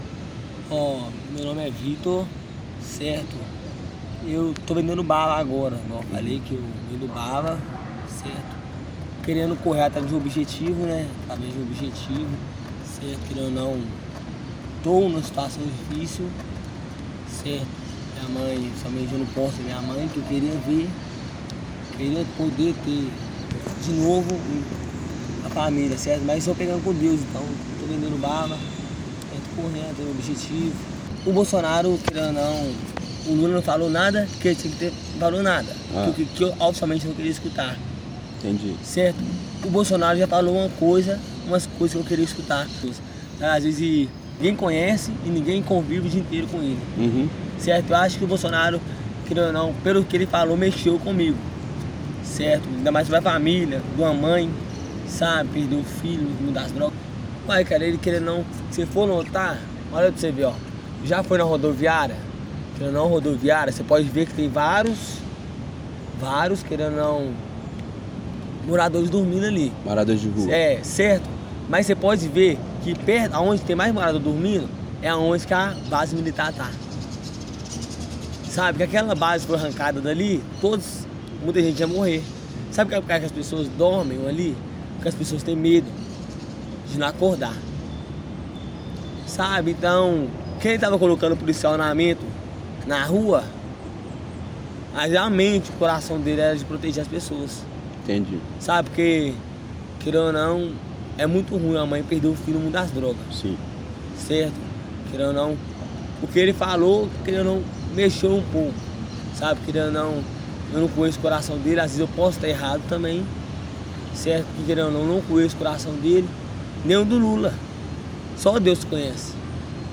dá depoimento em áudio à reportagem